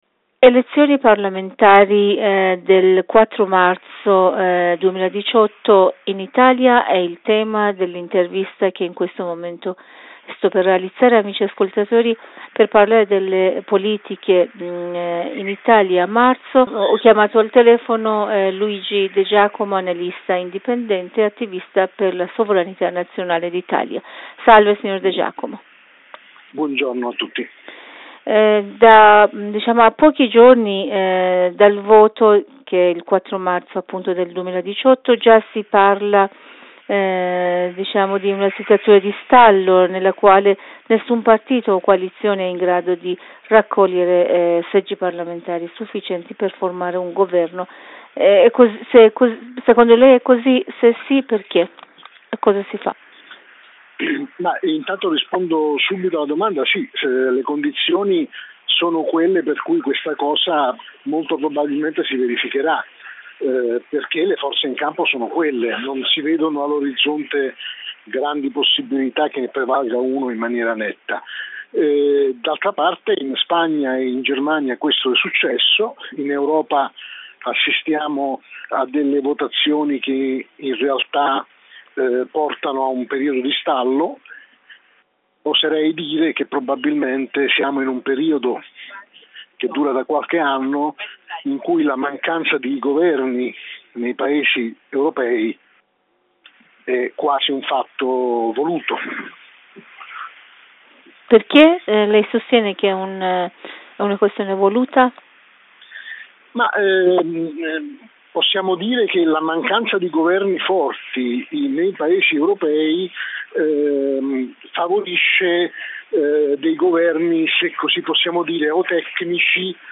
Potete ascoltare la versione integrale dell'intervista